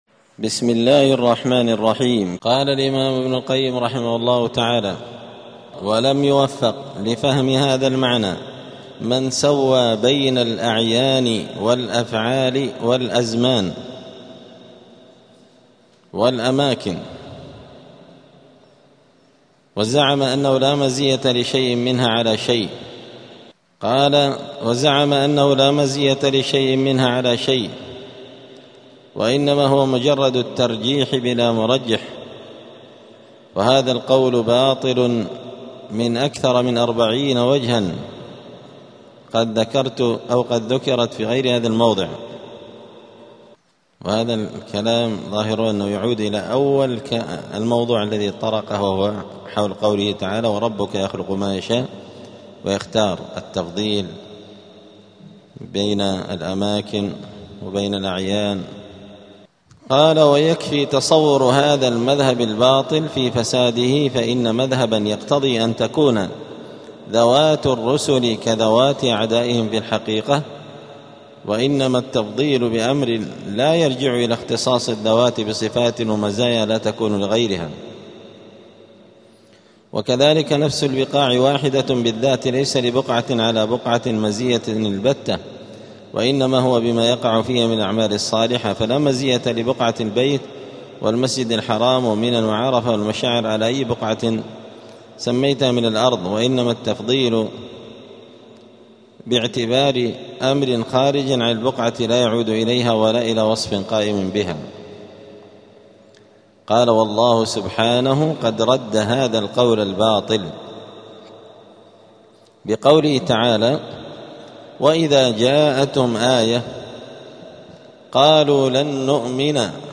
*الدرس السابع (7) {ﺗﻔﻀﻴﻞ ﺑﻌﺾ اﻷﻳﺎﻡ ﻭاﻟﺸﻬﻮﺭ ﻋﻠﻰ ﺑﻌﺾ}.*
دار الحديث السلفية بمسجد الفرقان قشن المهرة اليمن